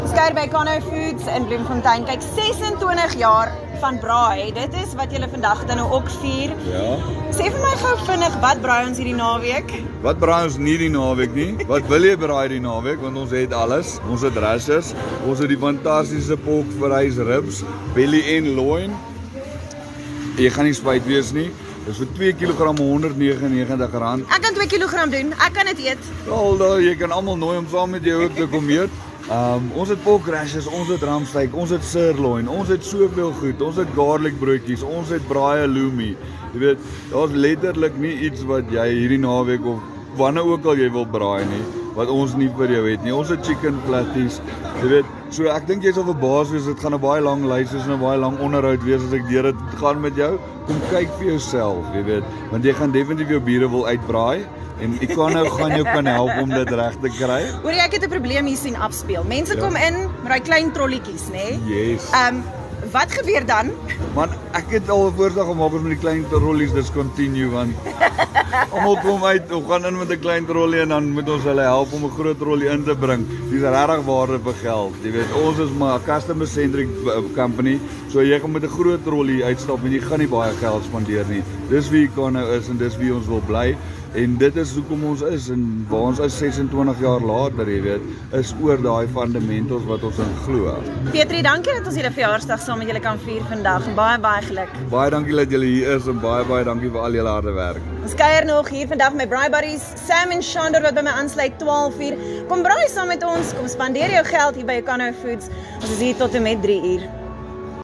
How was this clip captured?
OFM visits Econo Foods in Fauna Bloemfontein, on 9 September.